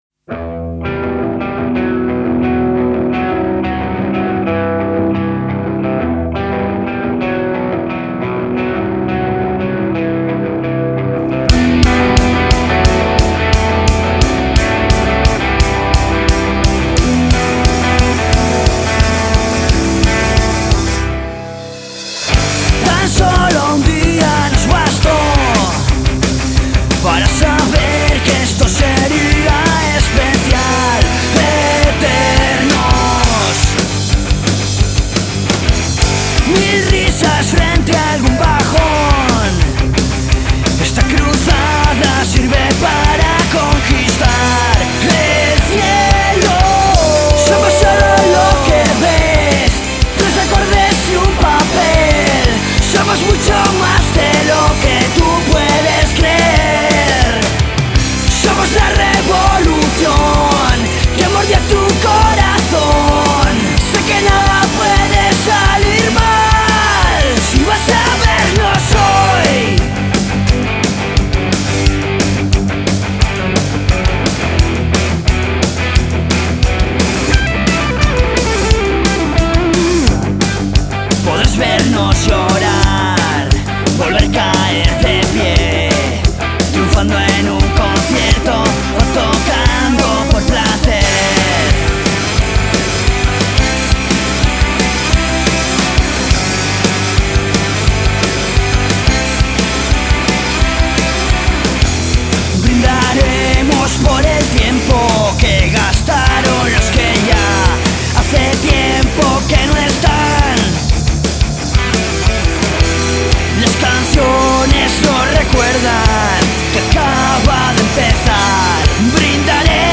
Rock en Español